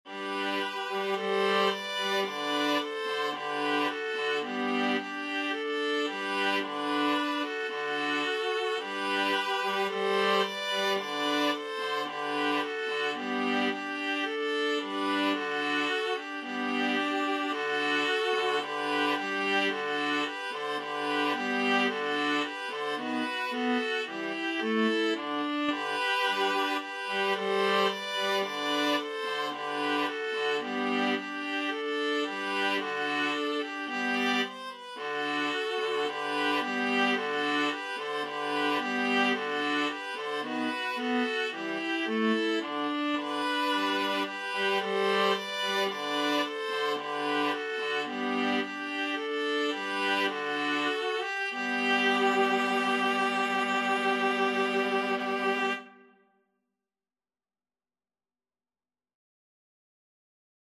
Free Sheet music for Viola Quartet
G major (Sounding Pitch) (View more G major Music for Viola Quartet )
4/4 (View more 4/4 Music)
= 110 Allegro assai (View more music marked Allegro)
Viola Quartet  (View more Easy Viola Quartet Music)
Classical (View more Classical Viola Quartet Music)
ode_to_joy_4VLA.mp3